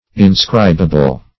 Meaning of inscribable. inscribable synonyms, pronunciation, spelling and more from Free Dictionary.
Search Result for " inscribable" : The Collaborative International Dictionary of English v.0.48: Inscribable \In*scrib"a*ble\, a. Capable of being inscribed, -- used specif.